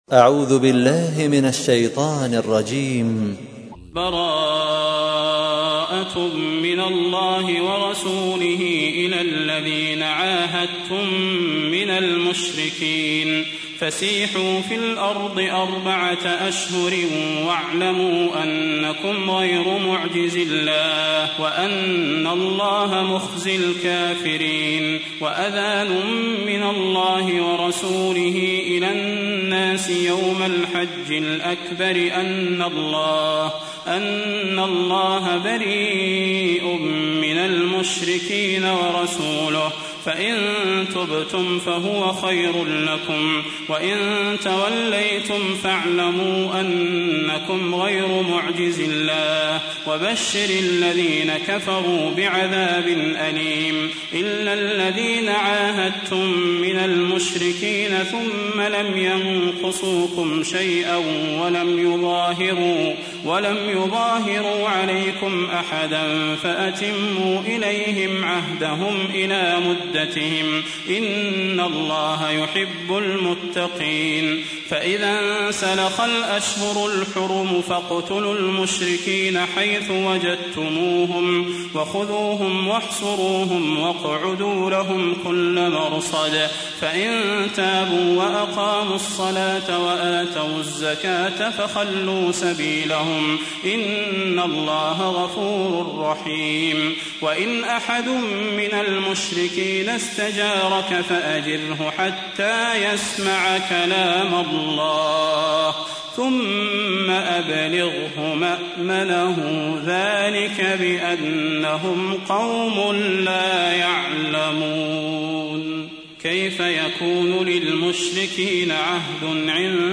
تحميل : 9. سورة التوبة / القارئ صلاح البدير / القرآن الكريم / موقع يا حسين